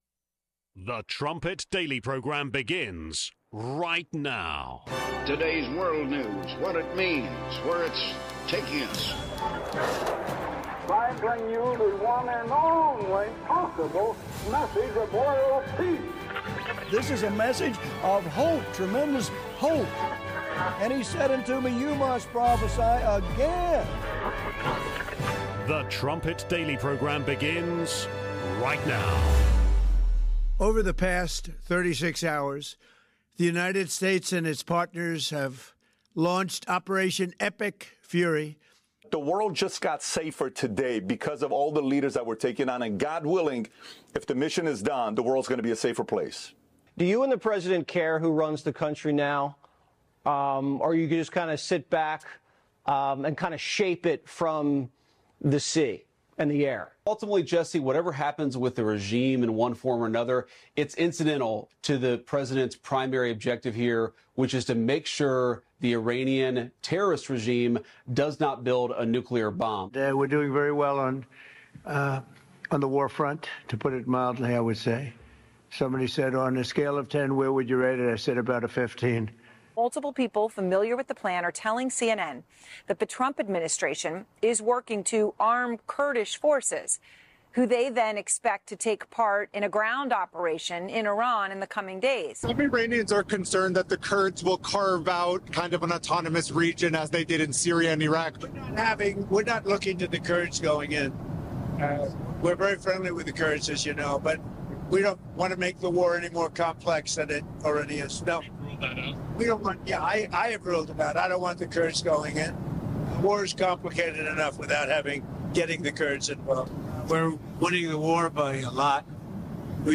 Trumpet Daily Radio Show brings you a deeper understanding of the Bible and how it connects to your world and your life right now.